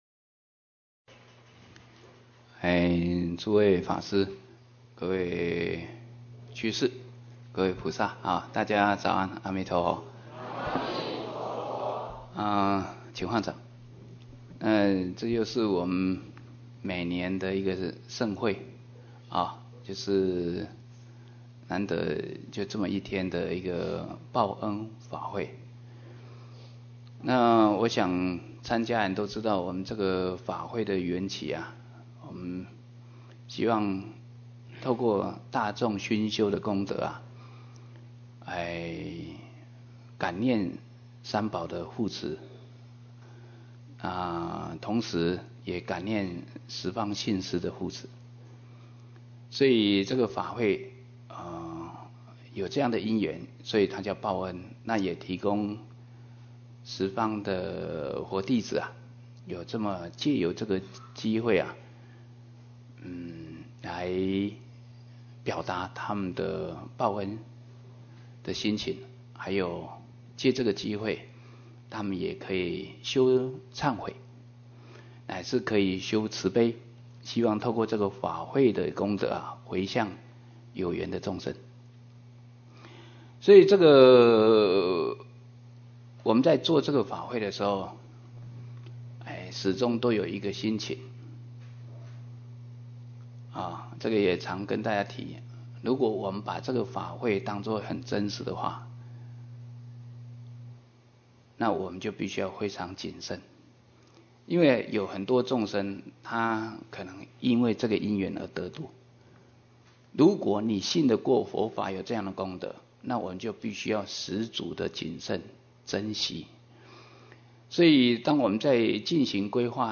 23报恩法会开示